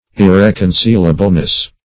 Meaning of irreconcilableness. irreconcilableness synonyms, pronunciation, spelling and more from Free Dictionary.